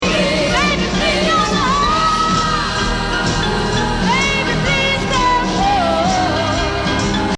Now the background singers echo her plea, “Please...”
The piano soars now.